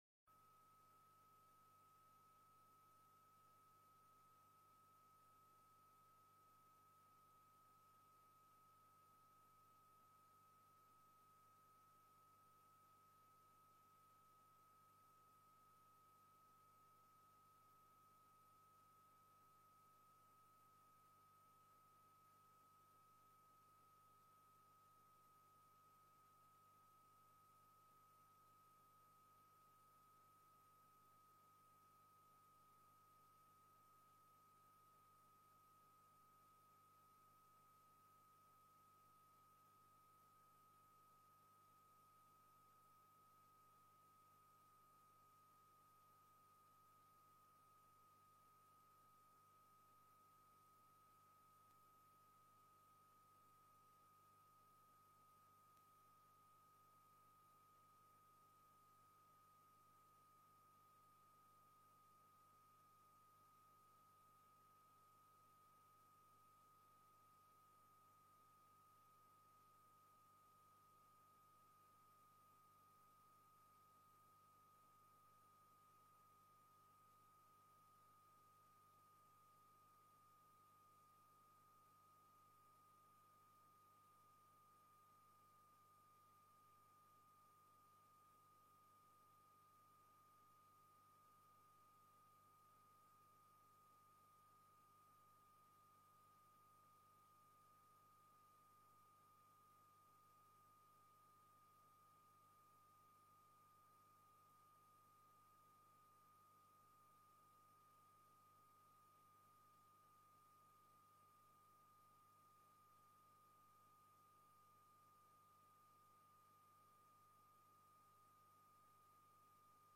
Locatie: Commissiekamer 1